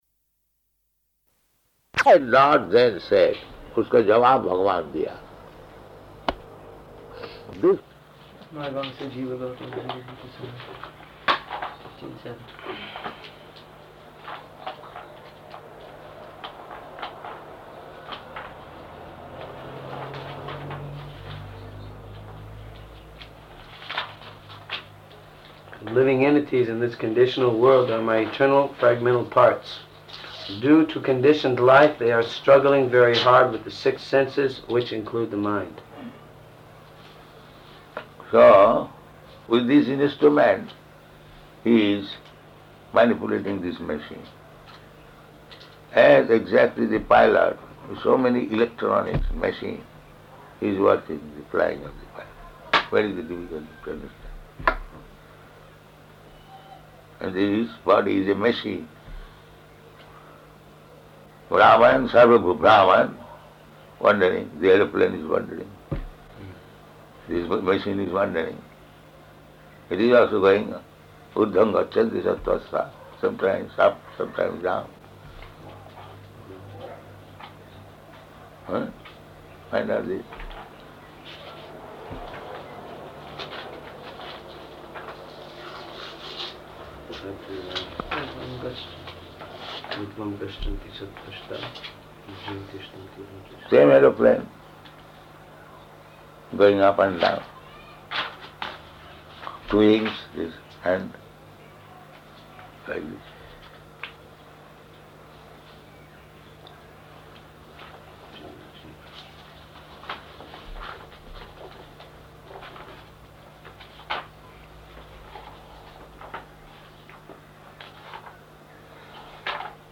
Room Conversation
Room Conversation --:-- --:-- Type: Conversation Dated: May 1st 1976 Location: Fiji Audio file: 760501R1.FIJ.mp3 Prabhupāda: ...the Lord then said, [Hindi:] ...bhagavān diyā.